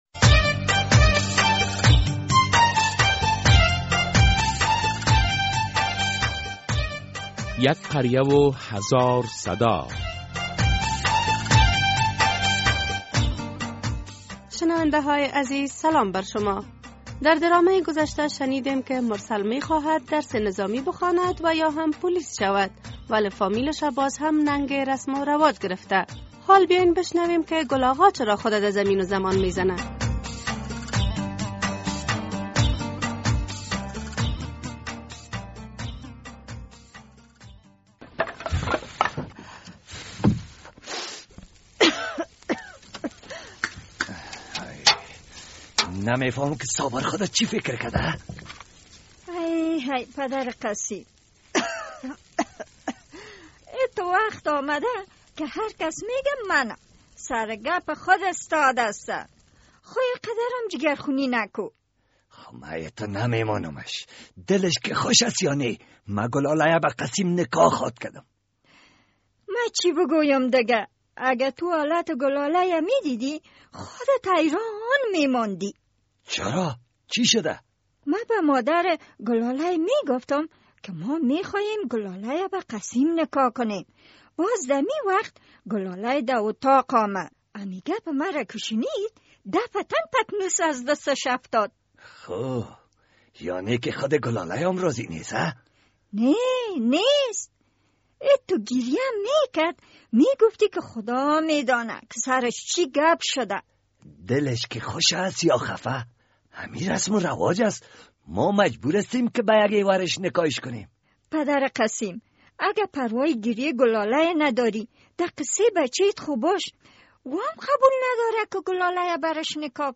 در قسمت ۱۸۴مین بخش درامه یک قریه هزار صدا در کنار موضوعات دیگر روی شرایط نکاح بحث شده است ...